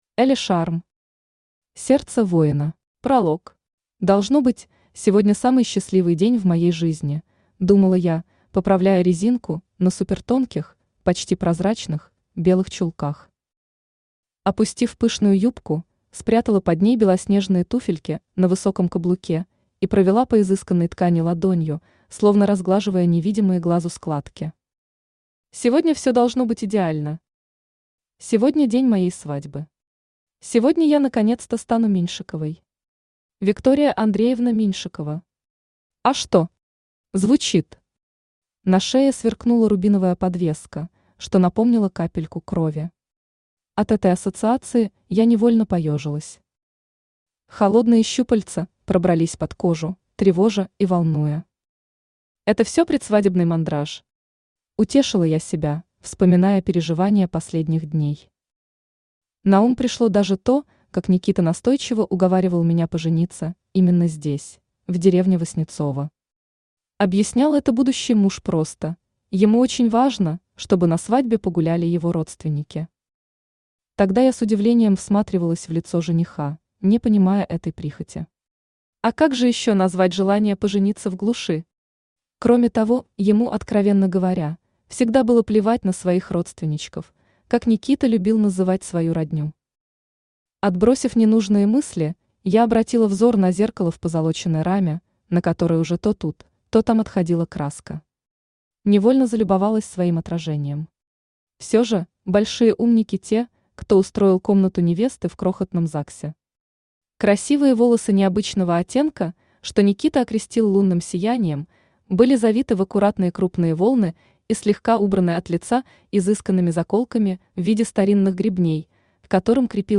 Аудиокнига Сердце Воина | Библиотека аудиокниг
Aудиокнига Сердце Воина Автор Элли Шарм Читает аудиокнигу Авточтец ЛитРес.